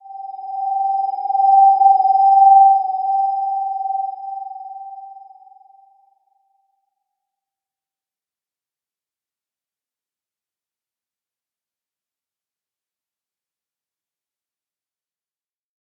Slow-Distant-Chime-G5-p.wav